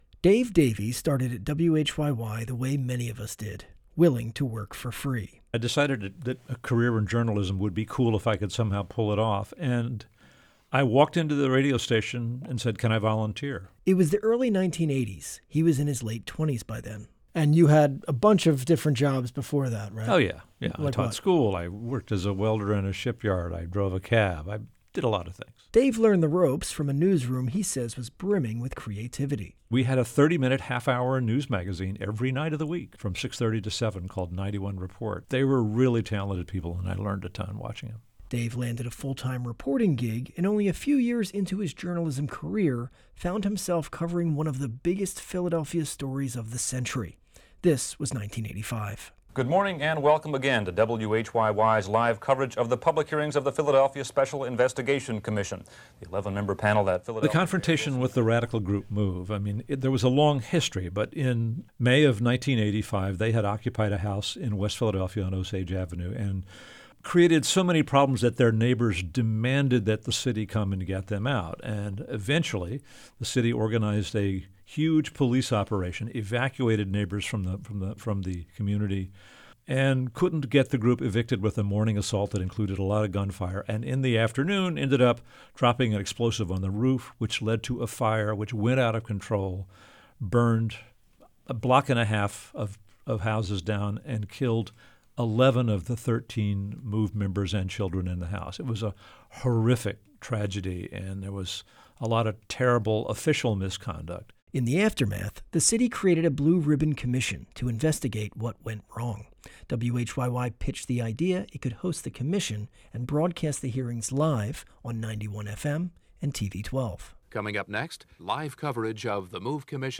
Guests: Edward Ayers